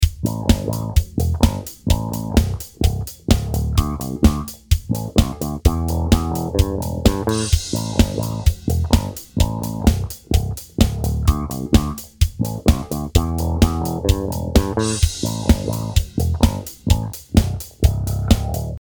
How do you like this funky bass tone?